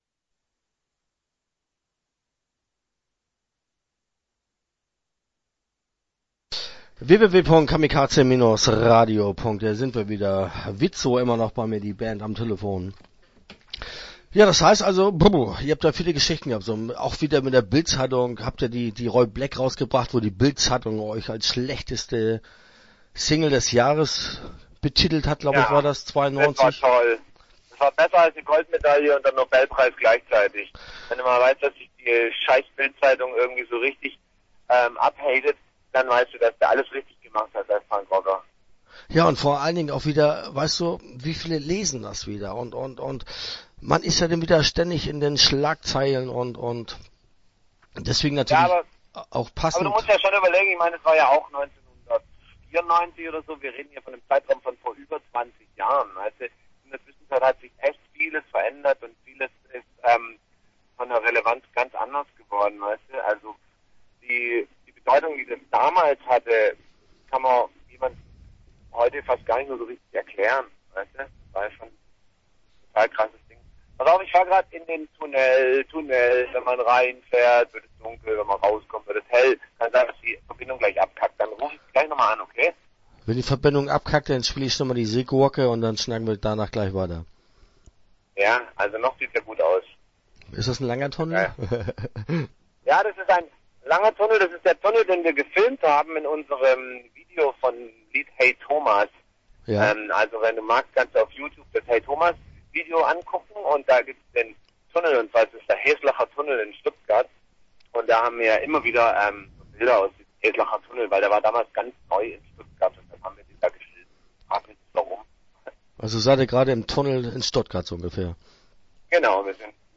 WIZO - Interview Teil 1 (13:52)